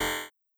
Error2.wav